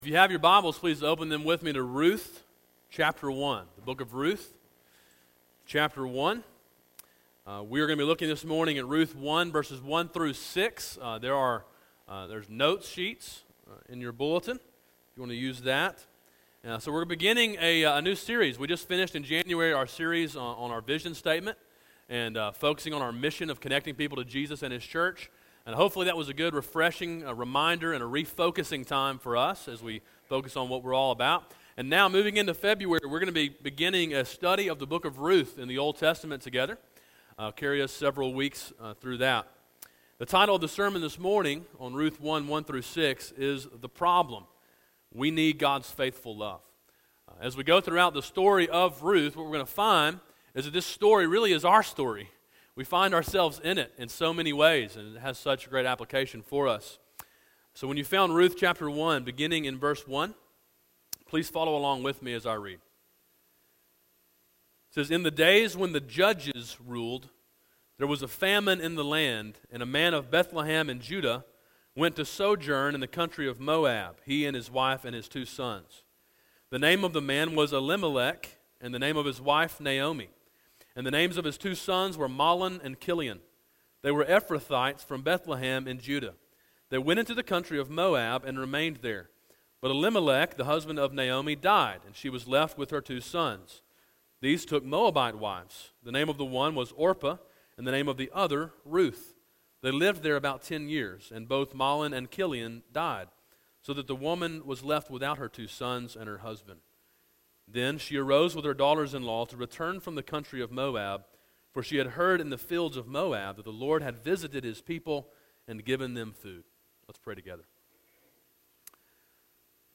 A sermon in a series on the book of Ruth.